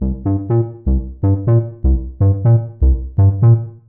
Hypno Riff_123_C.wav